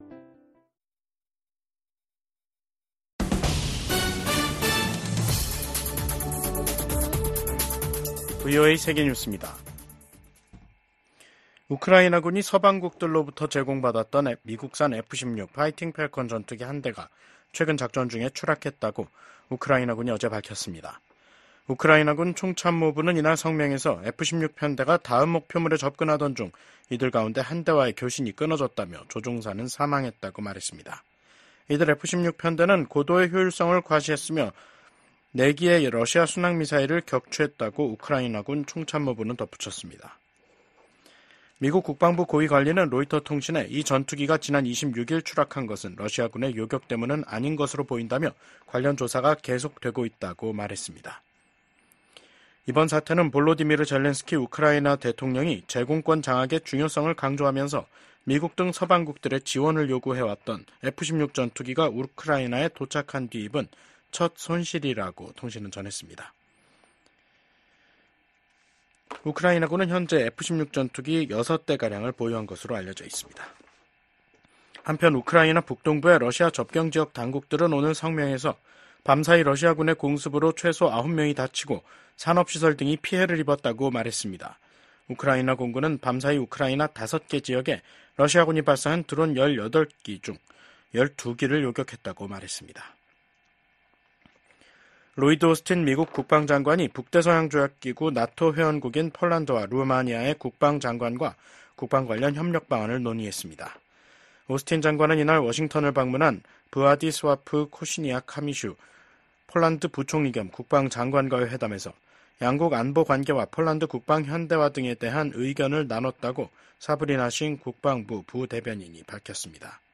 VOA 한국어 간판 뉴스 프로그램 '뉴스 투데이', 2024년 8월 30일 2부 방송입니다. 북한 해군 자산들이 국제해사기구(IMO) 자료에서 사라지고 있습니다.